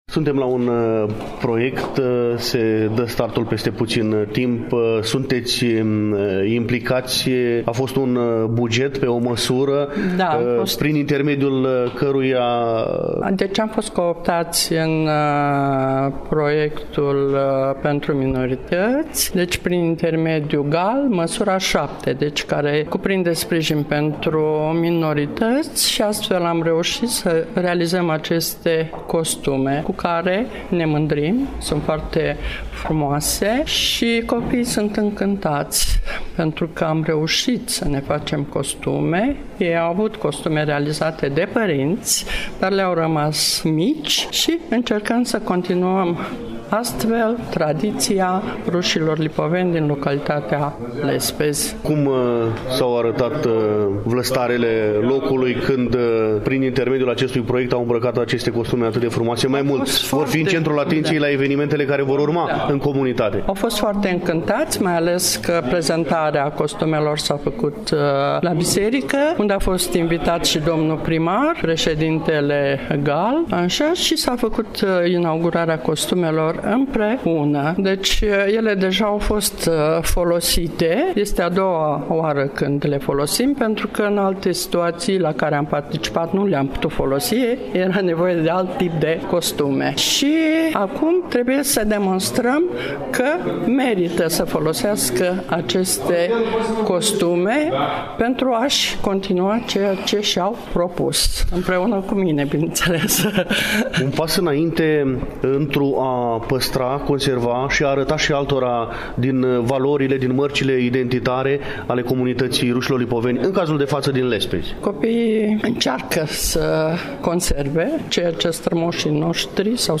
Evenimentul a avut loc în incinta Hanului Andri Popa din Comuna Valea Seacă, Iași, în ziua de vineri, 6 septembrie, începând cu ora 10.